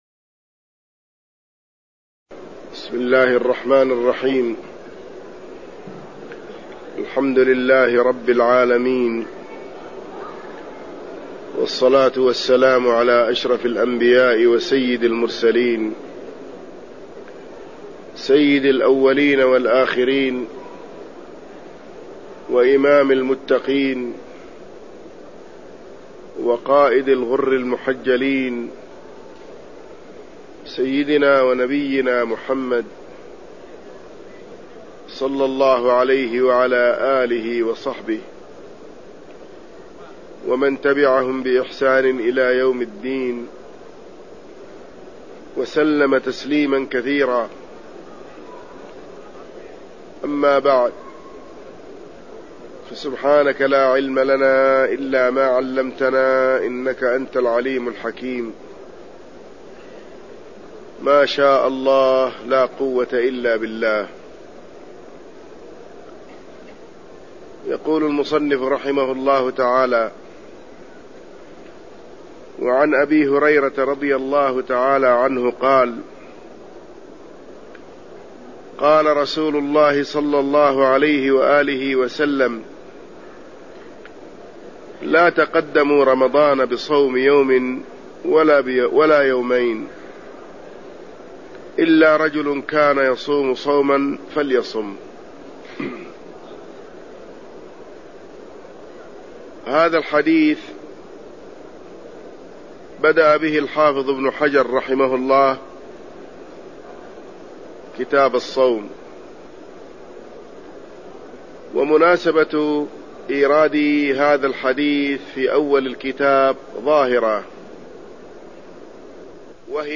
درس في الصيام
المكان: المسجد النبوي الشيخ